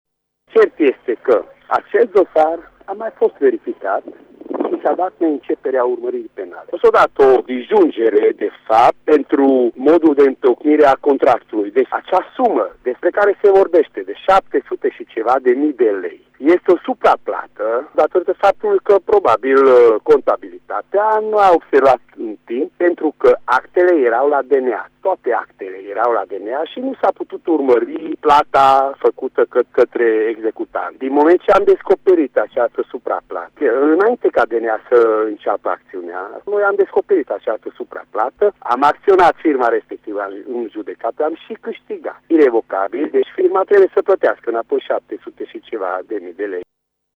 Contactat telefonic, Nagy Andras a declarat că nu se face vinovat de faptele reţinute în sarcina sa de către procurori şi a explicat că în dosar este vorba de o eroare contabilă care a şi fost ulterior clarificată: